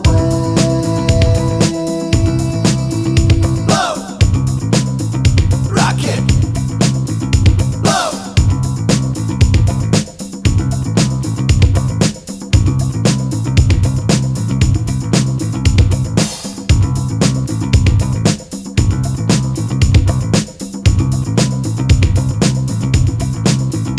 no Backing Vocals Dance 4:22 Buy £1.50